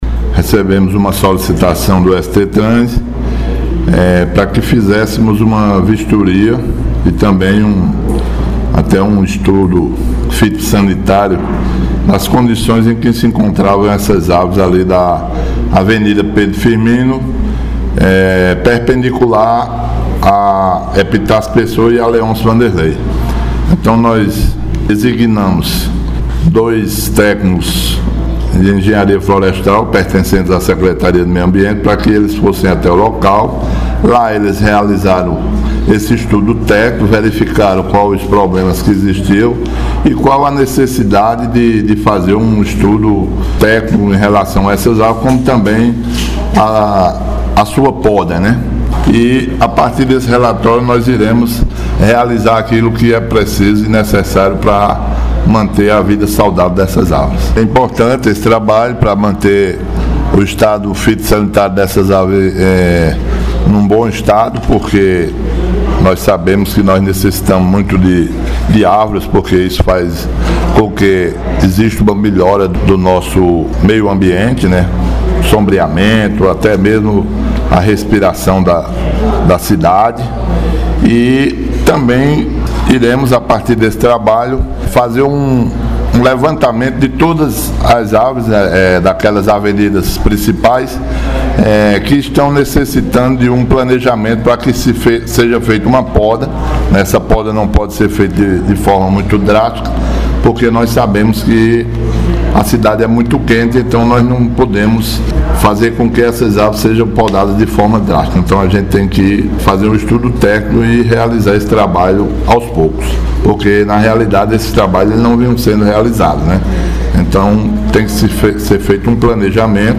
Fala do Secretário Natércio Alves